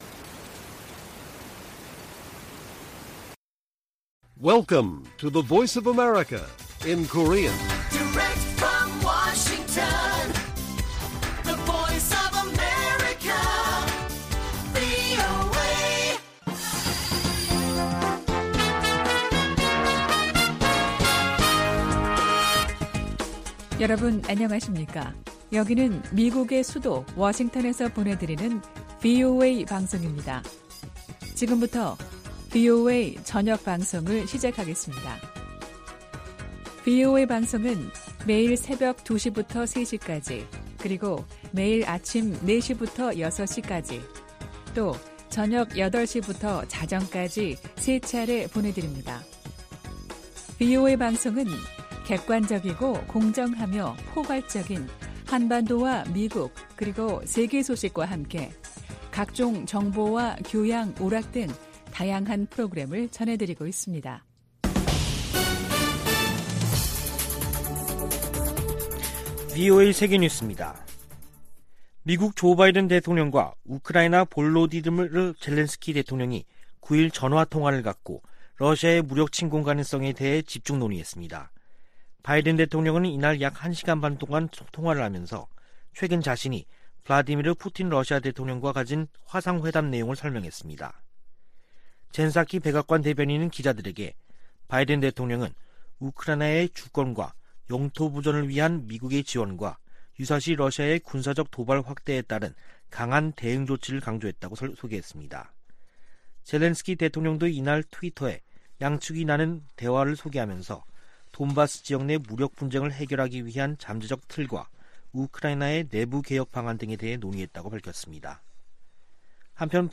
VOA 한국어 간판 뉴스 프로그램 '뉴스 투데이', 2021년 12월 10일 1부 방송입니다. 조 바이든 대통령은 세계 민주주의와 인권이 도전에 직면했다며 지속적인 노력이 필요하다고 강조했습니다. 올해도 유엔 안전보장이사회에서 북한 인권에 대한 공개 회의가 열리지 않는다고 미 국무부가 밝혔습니다. 북한에 각종 디지털 기기들이 보급되면서 외부 정보와 문화에 귀기울이는 젊은이들이 늘고 있습니다.